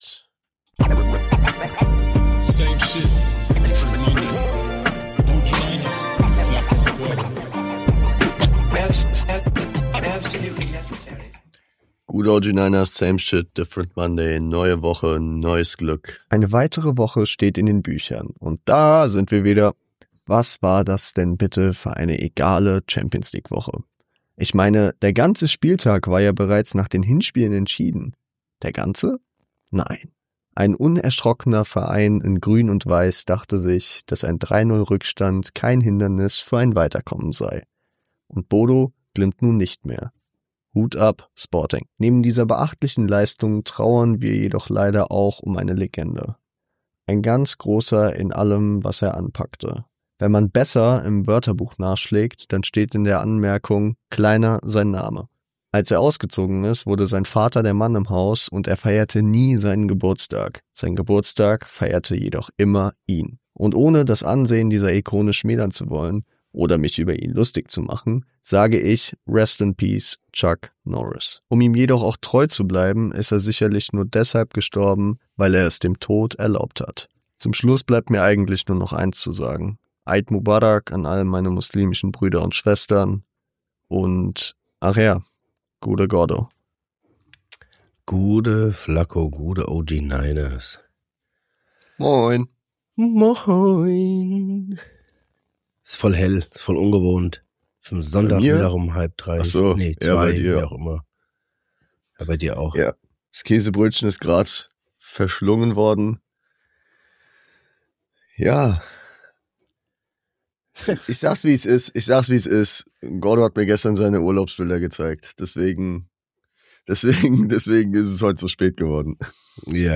Dennoch wurde auf einen Sonntag zwischen Käse-Brötchen und Bundesliga zumindest für eine kurze Folge das Mikrofon angeschlossen. Auf ein Neues stellen wir uns in der neuen Folge erneut die Frage, wer der King of Kotelett im Feststellen des Alters von Prominenten ist.